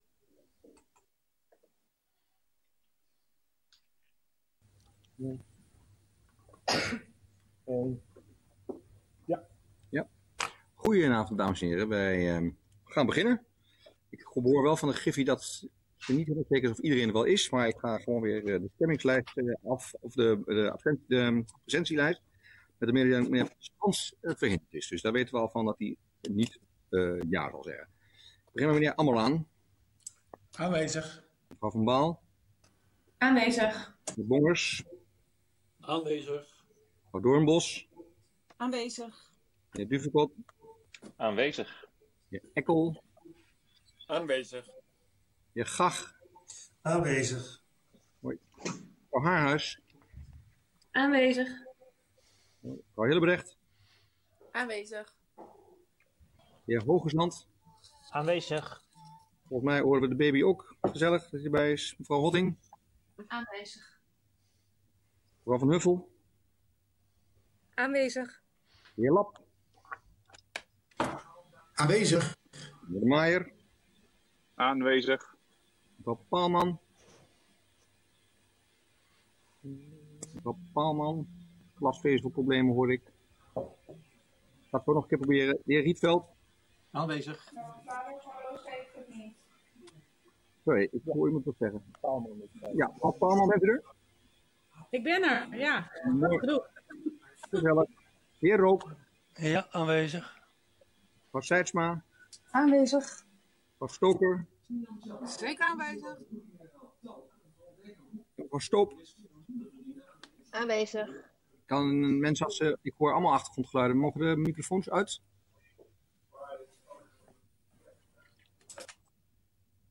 Raadsvergadering 17 december 2020 19:30:00, Gemeente Dronten
Deze vergadering wordt digitaal gehouden en is hieronder via de live stream te volgen.